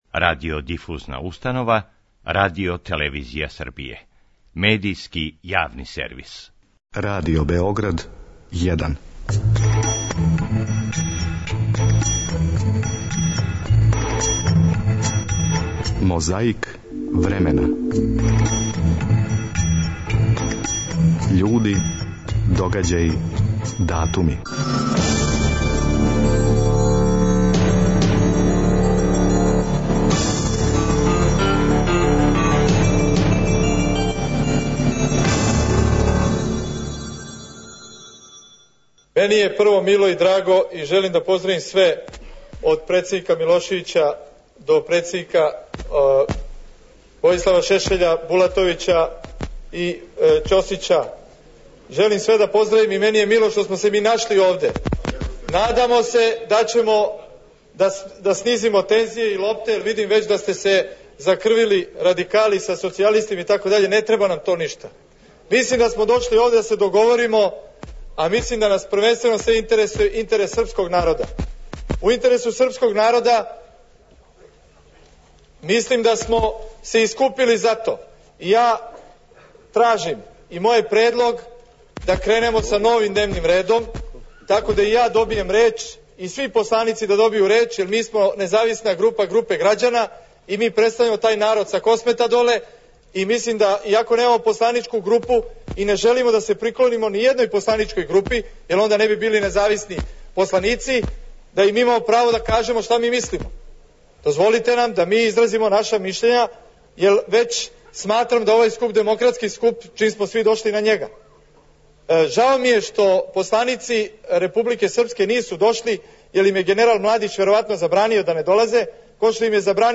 Конститутивна седница Скупштине Србије на којој је потврђен мандат владе Војислава Коштунице, одржана је 15. маја 2007. године. Слушамо део експозеа.